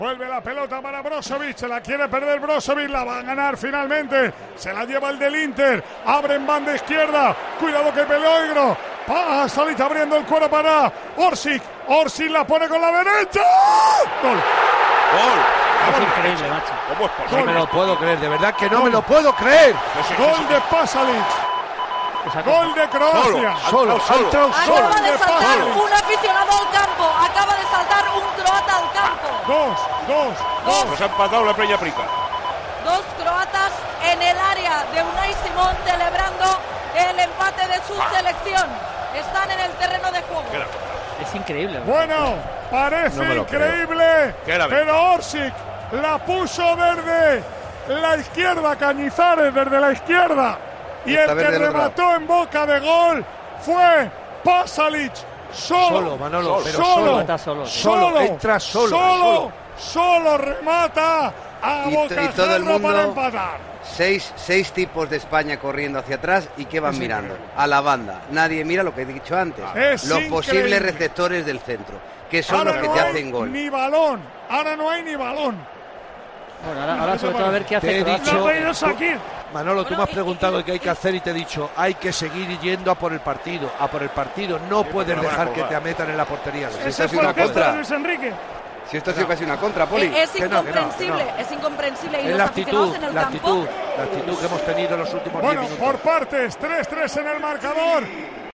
ASÍ NARRÓ MANOLO LAMA LOS GOLES DE CROACIA, 5 - ESPAÑA, 3